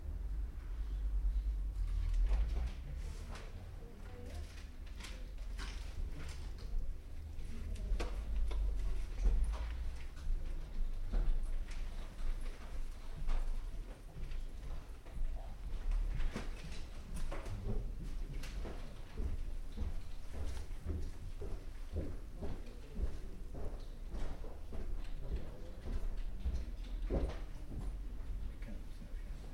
На этой странице собраны звуки библиотеки: тихий шелест страниц, шаги между стеллажами, отдаленные голоса читателей.
Шум библиотеки в университете где выдают книги